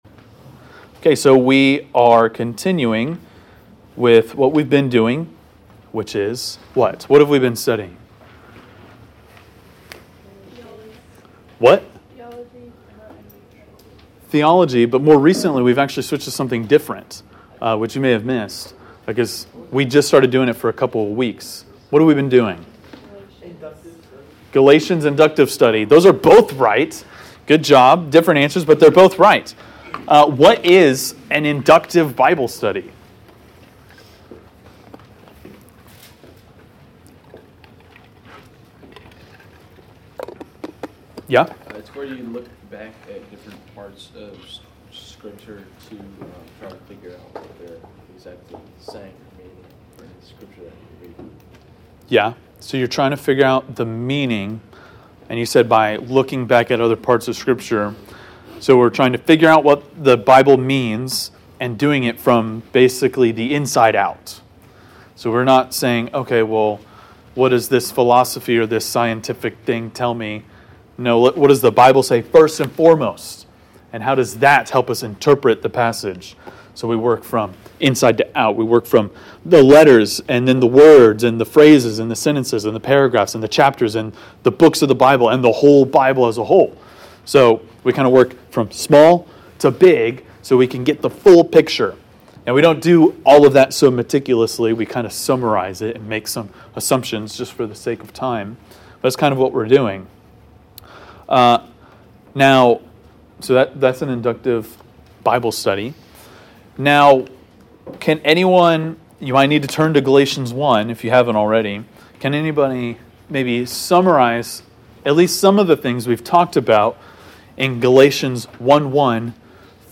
Galatians 1:10-17 (Inductive Bible Study)